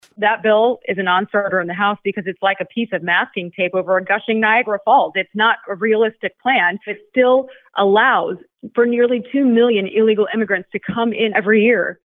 Hinson made her comments during her weekly conference call with reporters and says Harris’s stance on the southern border had been virtually nonexistent before the convention.